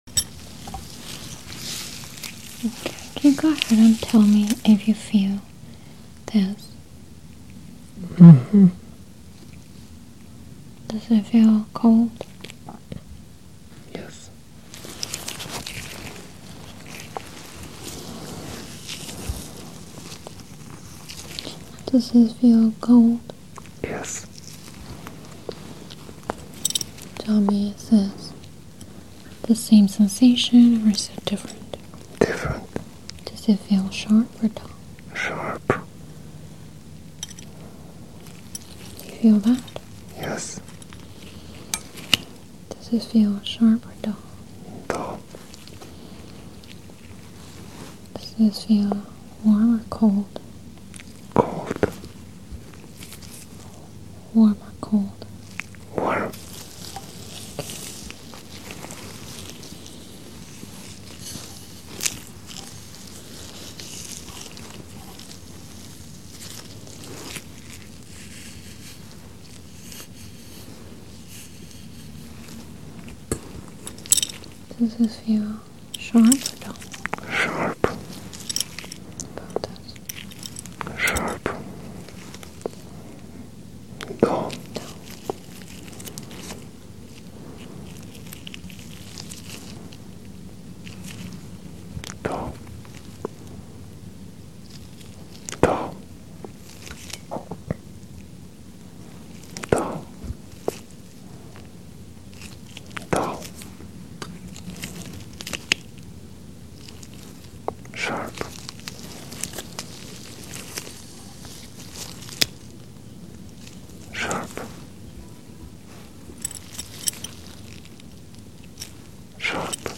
ASMR | Hair Play and sound effects free download